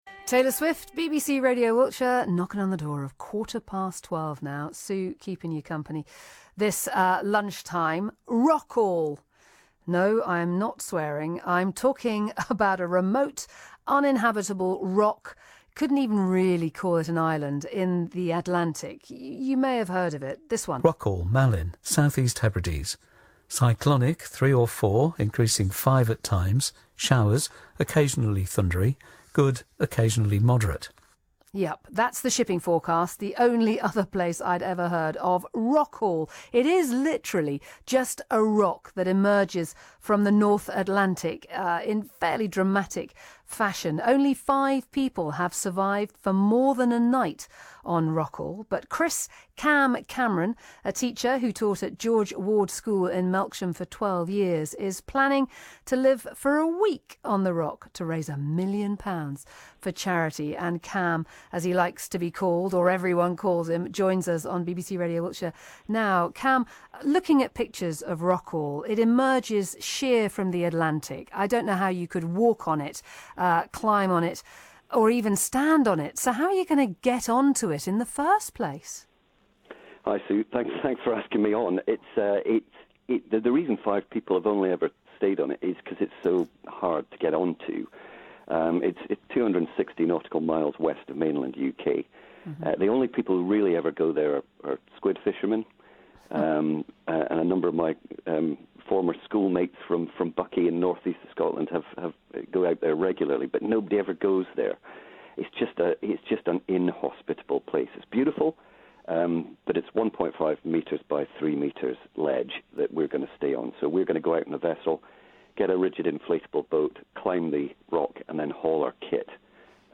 BBC Radio Wiltshire Interview - Rockall 2023